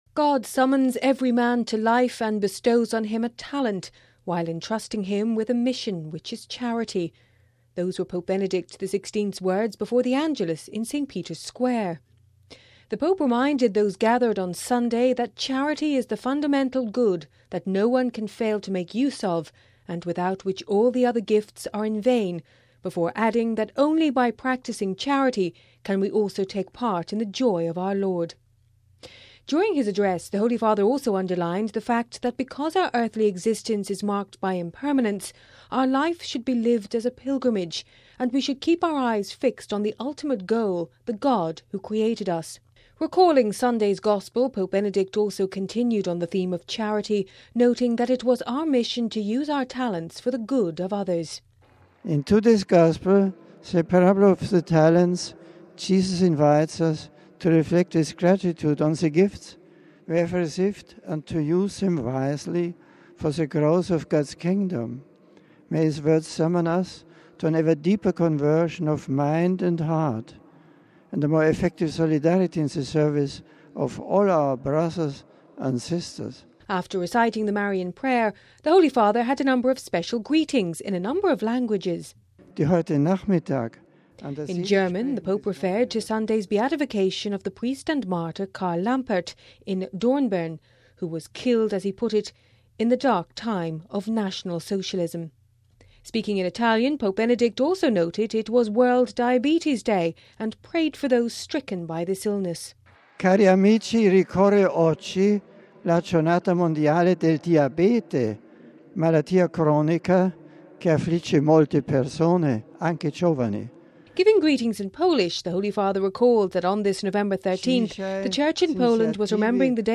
Those were Pope Benedict XVI’s words before the Angelus in St Peter’s Square.